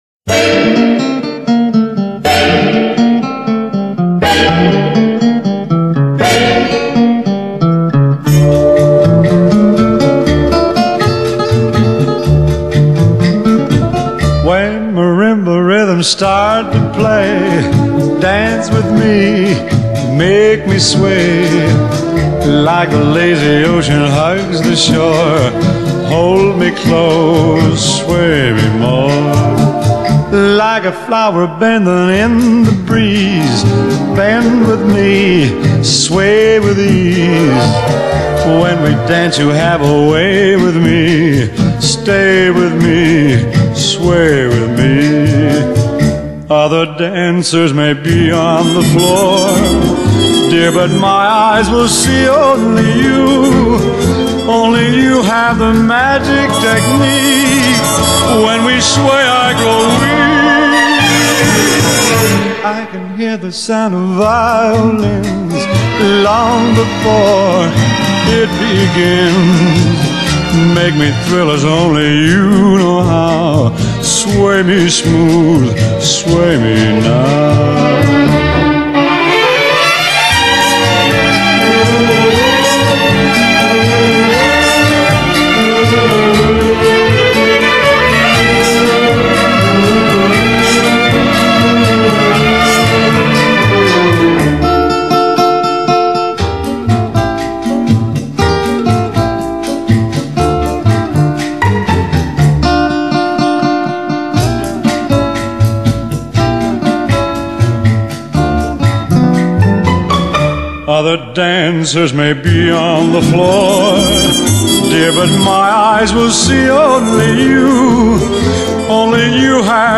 Genre: Jazz / Vocal Jazz